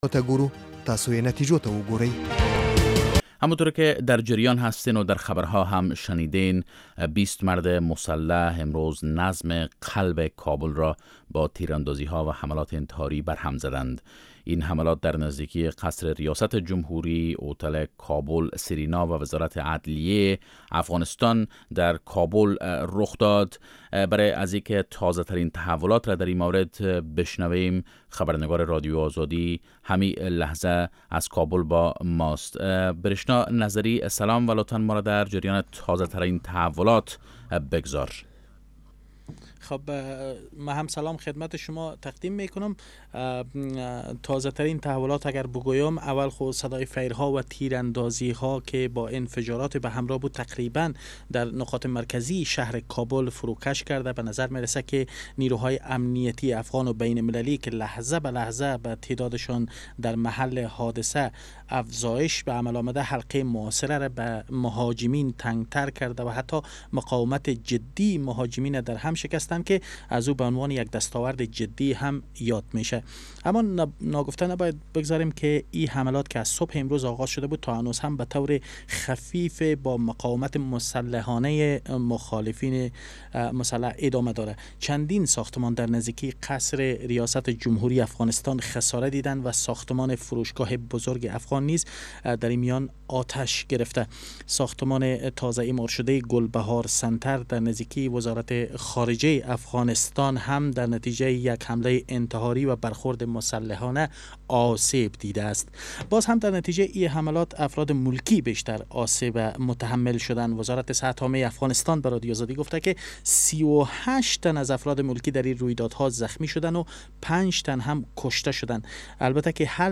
گزارشگر رادیو آزادی از شهر کابل در مورد آخرین تحولات امنیتی گزارش می دهد